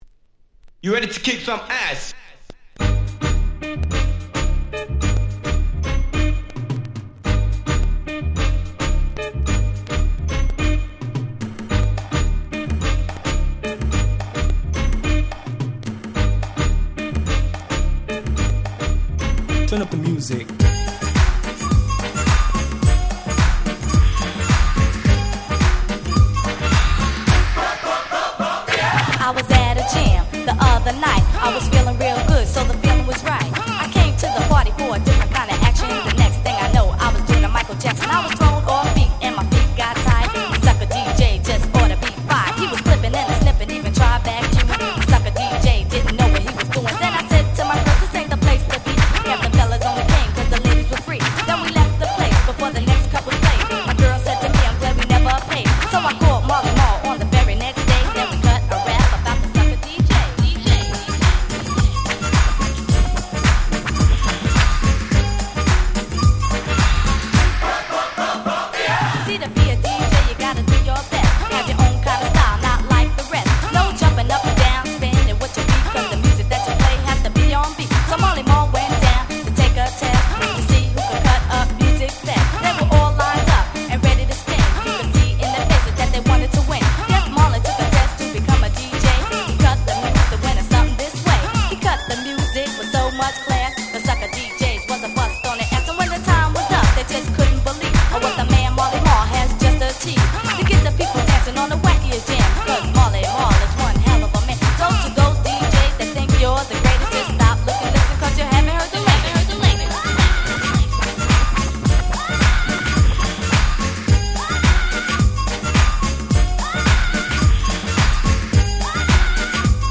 90' Smash Hit Hip Hop.
ディンプレスディー 90's ニュースクール New School Boom Bap ブーンバップ